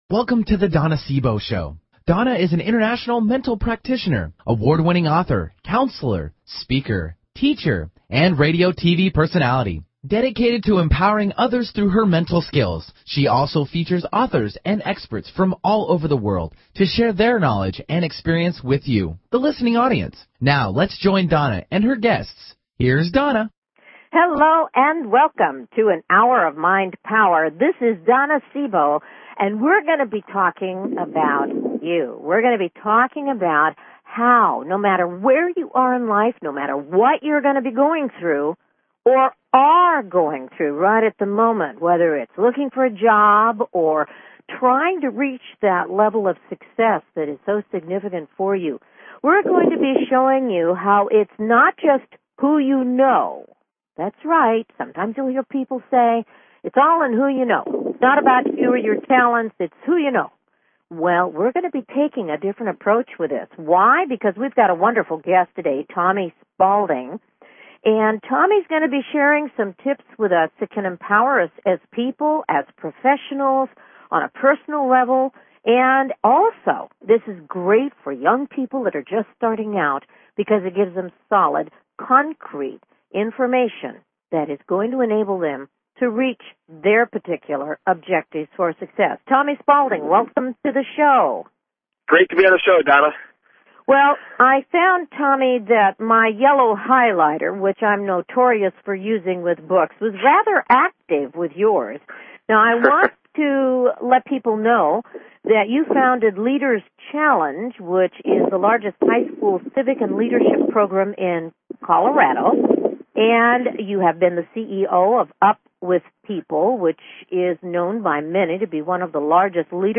Talk Show
Her interviews embody a golden voice that shines with passion, purpose, sincerity and humor.
Tune in for an "Hour of Mind Power". Callers are welcome to call in for a live on air psychic reading during the second half hour of each show.